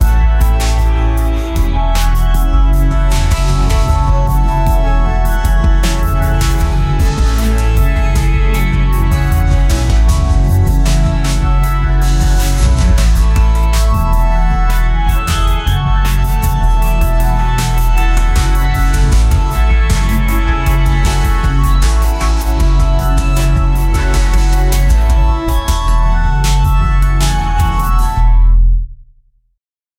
the background music for the ad video is positive